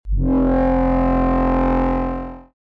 Bass Tone (Before)
Bass-Tone-Before.mp3